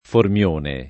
Formione [ form L1 ne ]